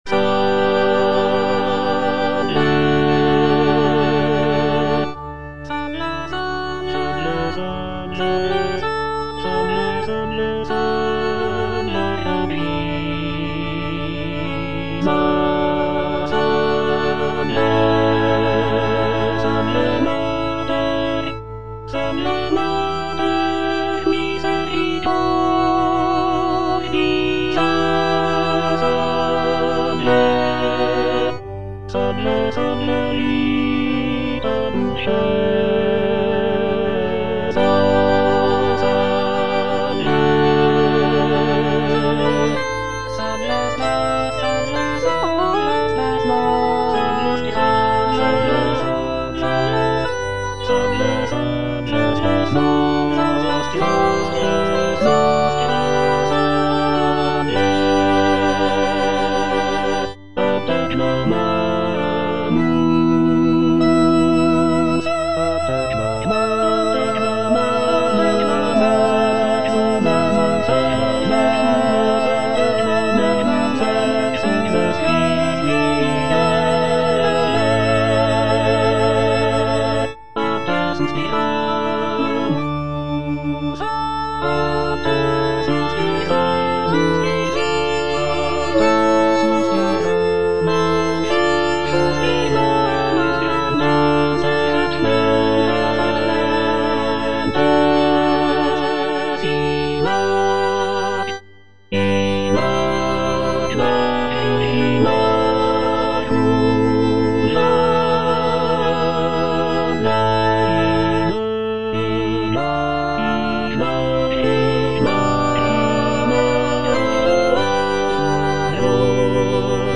G.F. SANCES - SALVE, REGINA (All voices) Ads stop: auto-stop
"Salve, Regina" by Giovanni Felice Sances is a sacred vocal work written in the 17th century.
The piece is written for soprano soloist and continuo accompaniment, and features a lyrical and expressive melody that showcases the singer's vocal abilities.